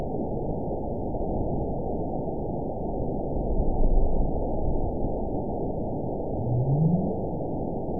event 920549 date 03/29/24 time 23:13:20 GMT (1 year, 1 month ago) score 9.60 location TSS-AB01 detected by nrw target species NRW annotations +NRW Spectrogram: Frequency (kHz) vs. Time (s) audio not available .wav